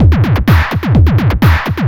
DS 127-BPM A4.wav